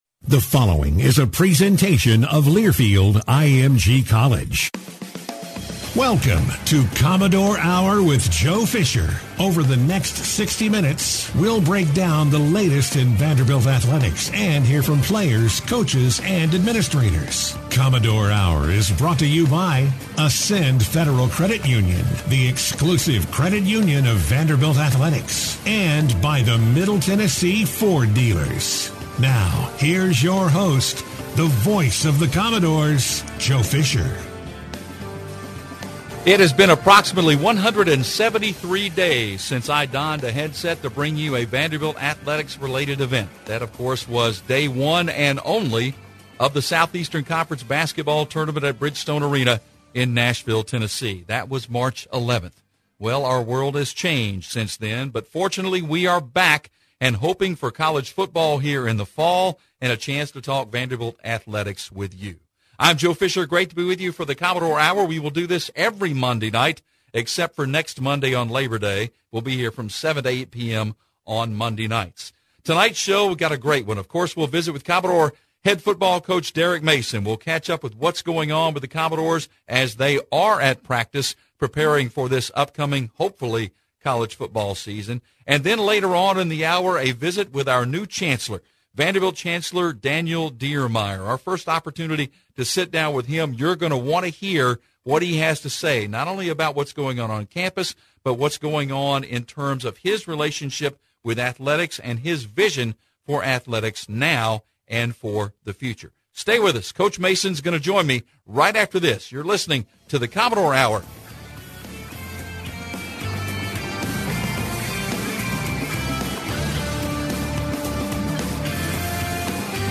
-1:45, Vanderbilt football coach Derek Mason joins the show for his weekly visit discussing the football offseason, the 2020 roster, and training camp with COVID protocols.